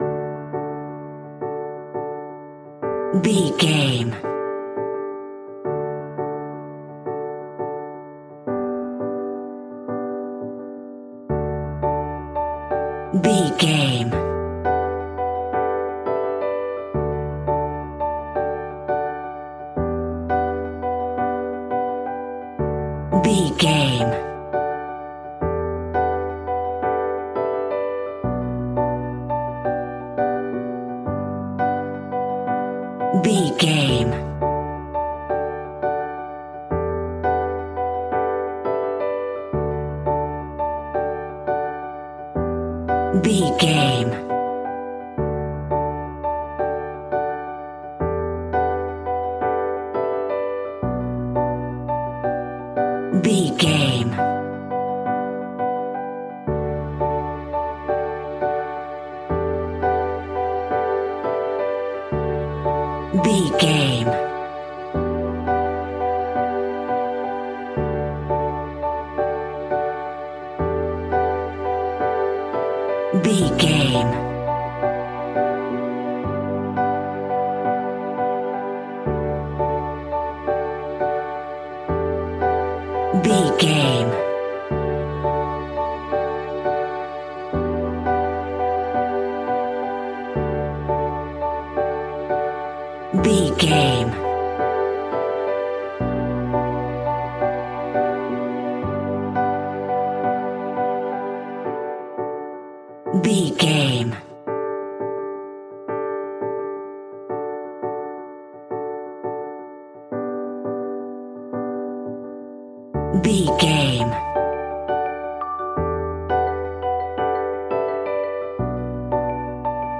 Ionian/Major
D
pop
pop rock
indie pop
fun
energetic
uplifting
cheesy
instrumentals
upbeat
uptempo
groovy
guitars
bass
drums
piano
organ